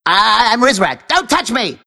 Worms speechbanks
Yessir.wav